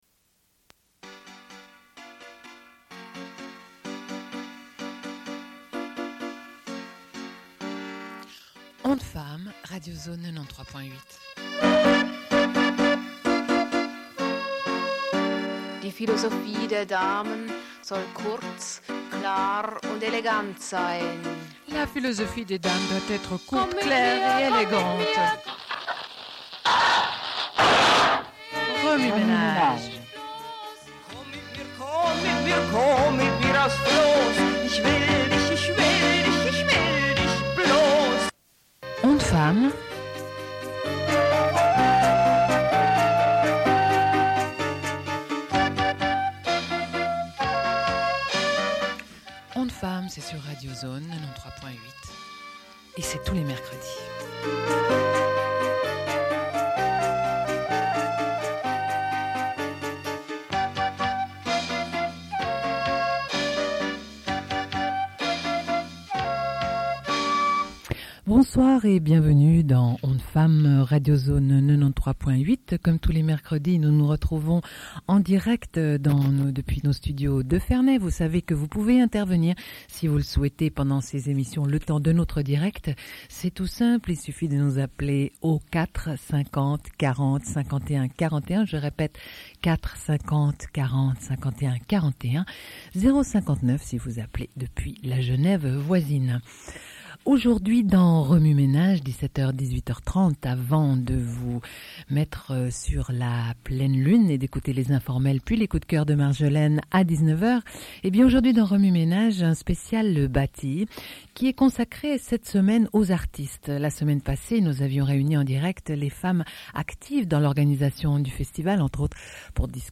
Radio Enregistrement sonore